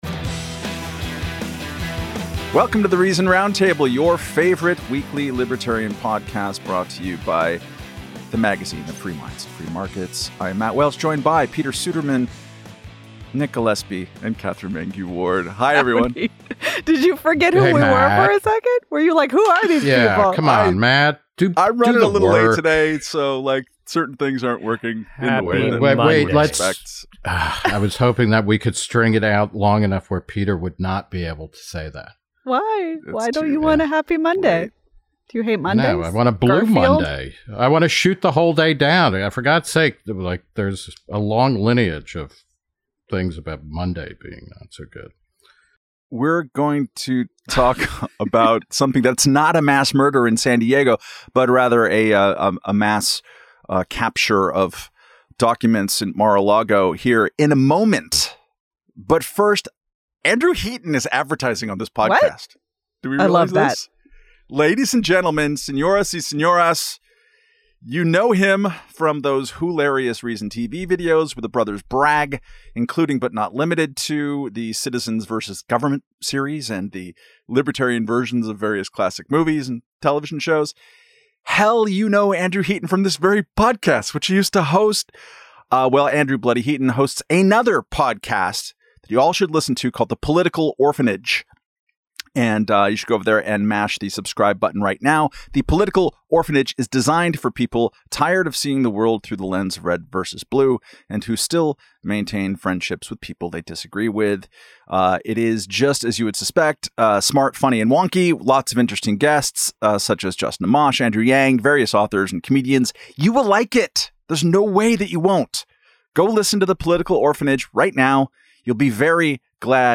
In this week's The Reason Roundtable, panelists huddle on last week's FBI raid of former President Donald Trump's Mar-a-Lago estate.